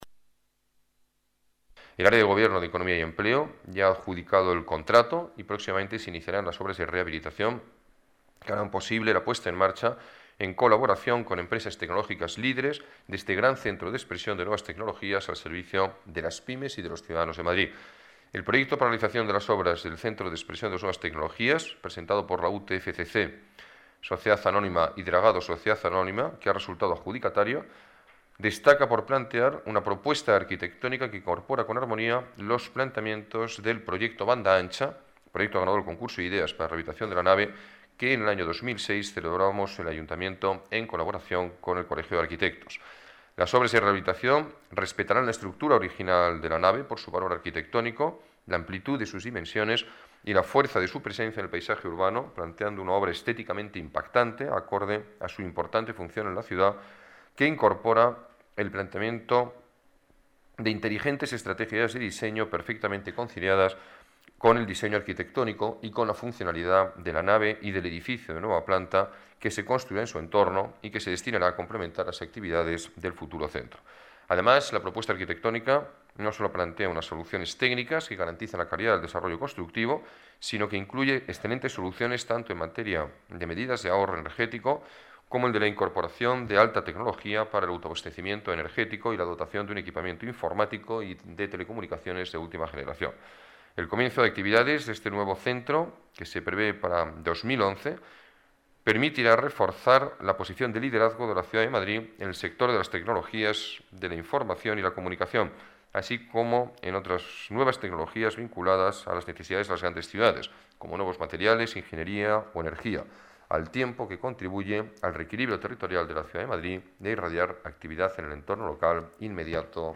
Nueva ventana:Declaraciones del alcalde de Madrid, Alberto Ruiz-Gallardón: Centro Nuevas Tecnologías